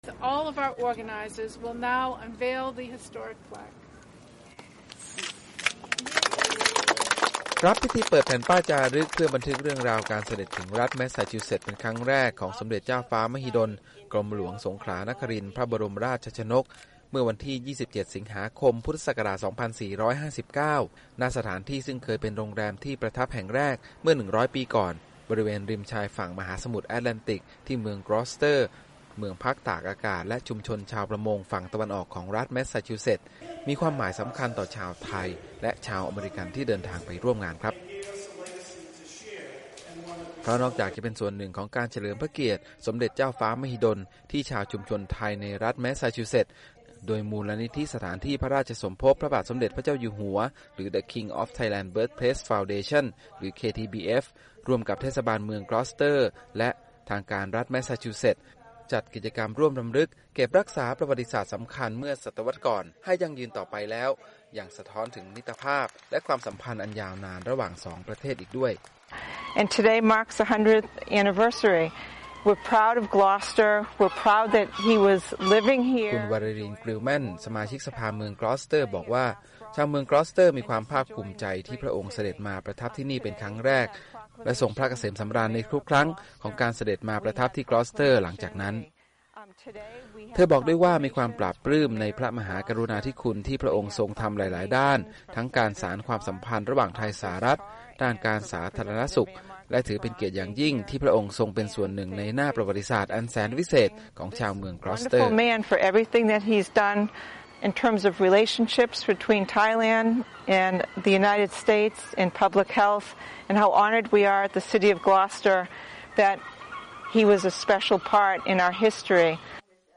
PT Report Sunday Boston